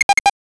NOTIFICATION_8bit_04_mono.wav